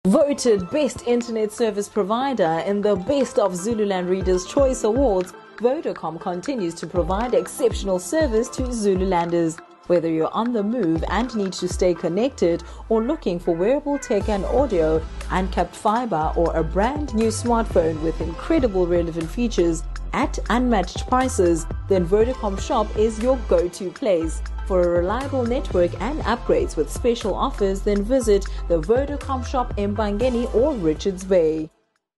authentic, authoritative, soothing
Vodacom advert demo